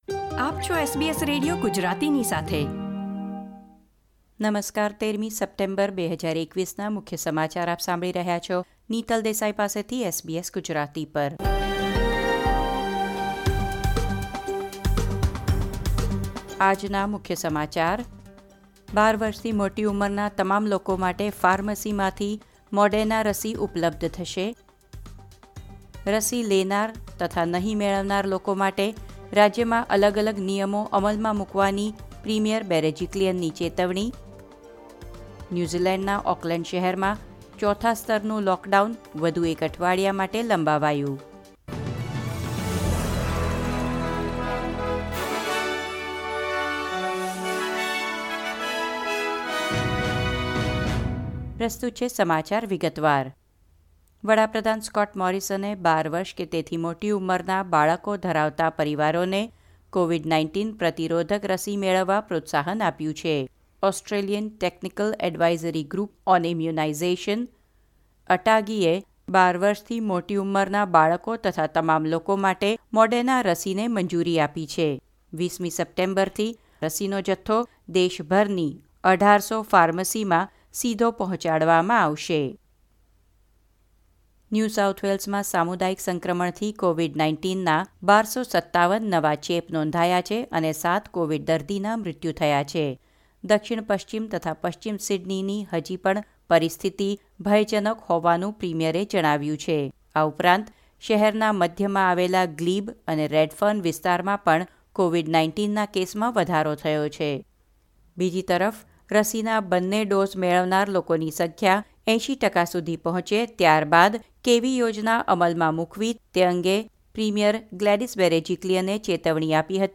SBS Gujarati News Bulletin 13 September 2021